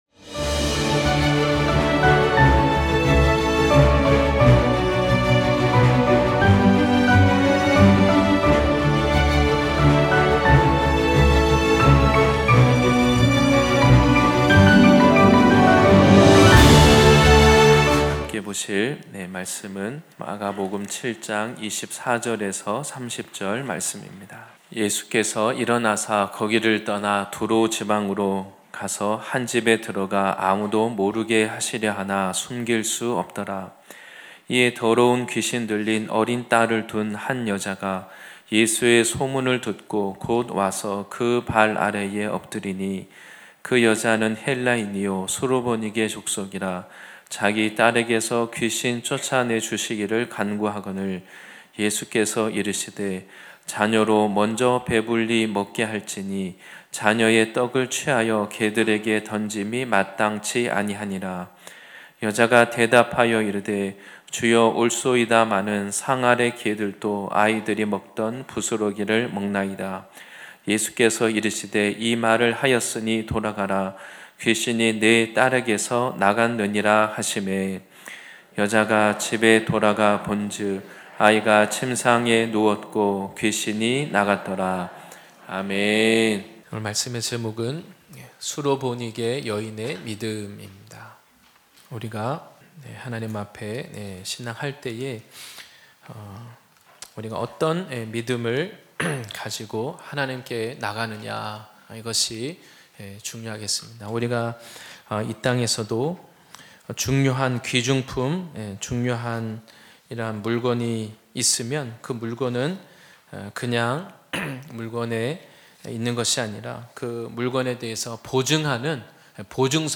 주일예배말씀